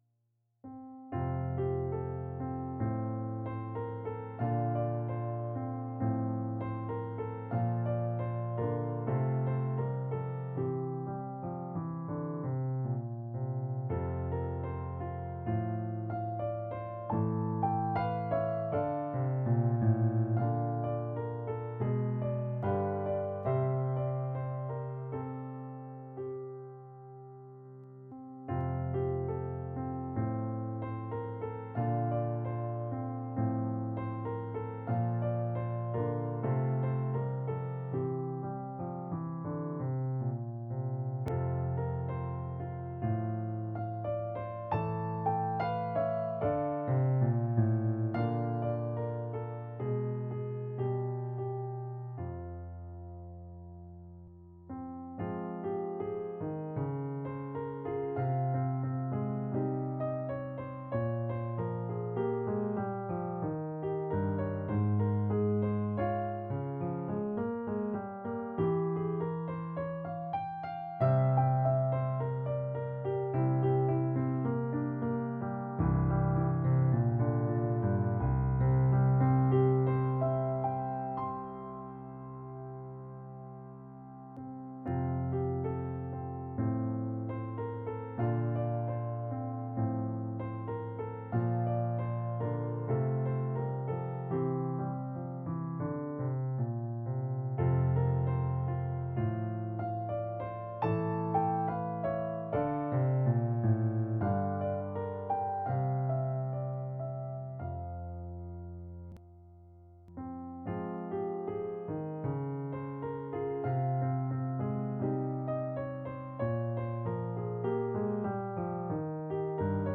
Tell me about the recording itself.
Piano piece draft played in digital piano (need suggestion for turning themes into full pieces)